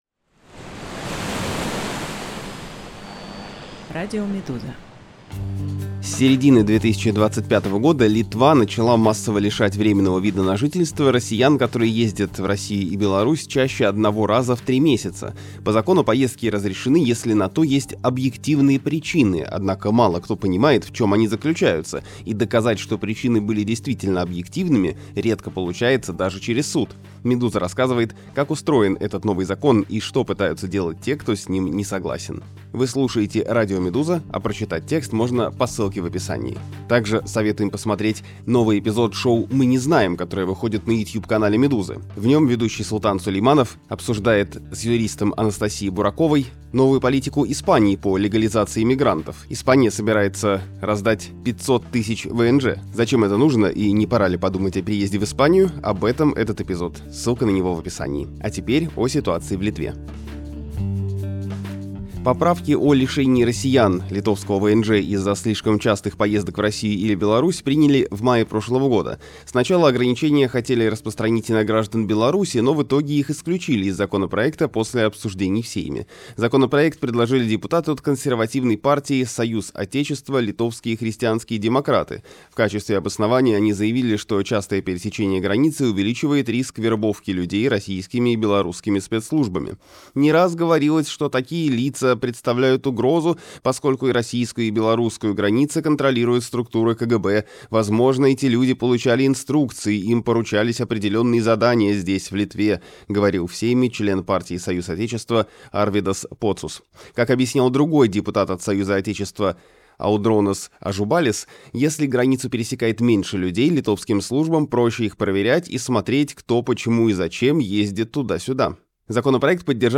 Аудиоверсию этого текста слушайте на «Радио Медуза» подкасты За что Литва лишает россиян ВНЖ?